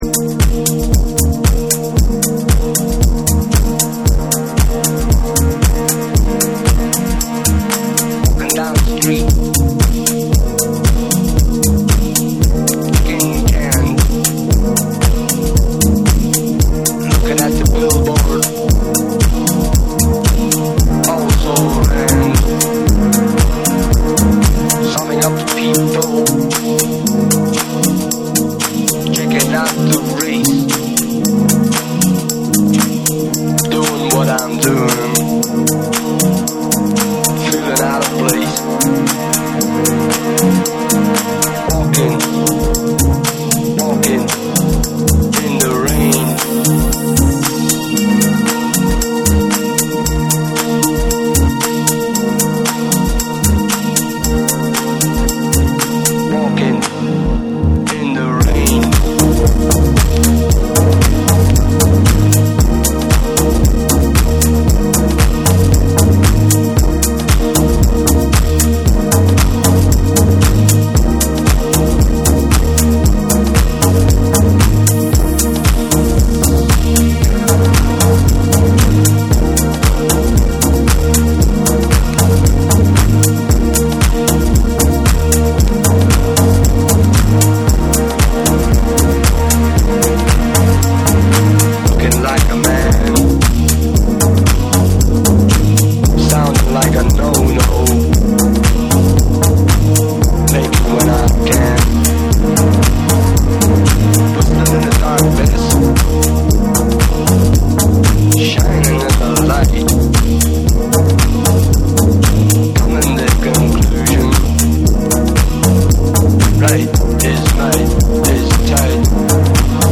低音がしっかり効いた4/4トラックの上に、広がりを持つシンセが心地よく舞い、リスナーを空間的な広がりへと誘う2。
TECHNO & HOUSE